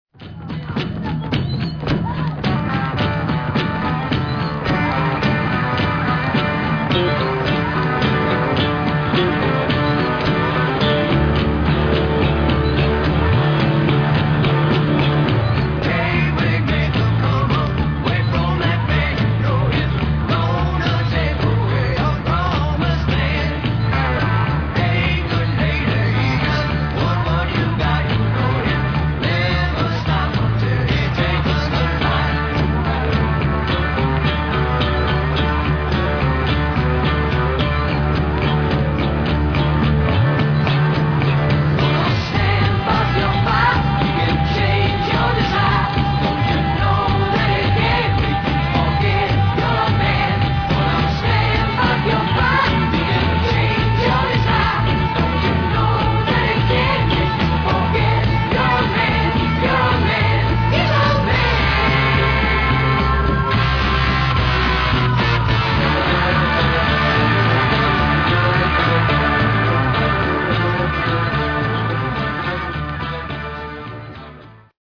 Mono (Default for U.S. 45s before 1973)Other info